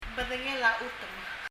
bedengel a chutem[bədəŋəl ə ! utəm]茶色brown